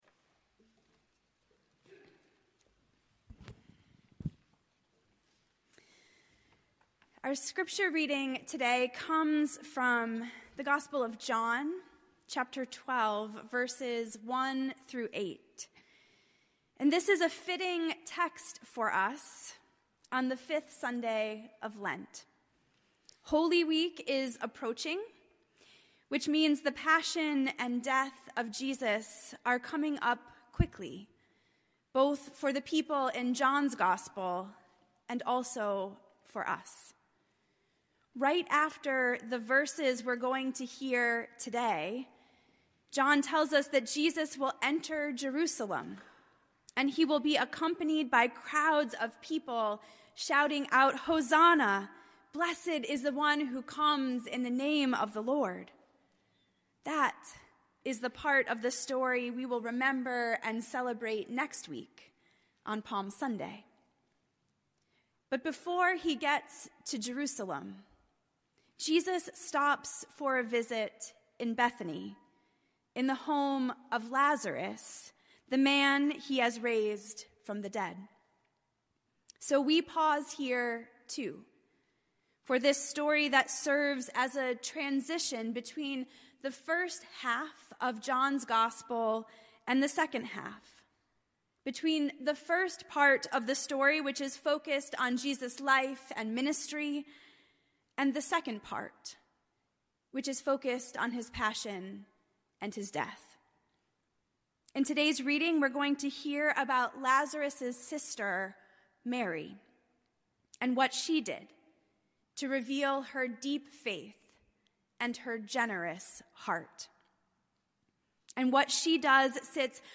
Sermons - Old South Union Church – Weymouth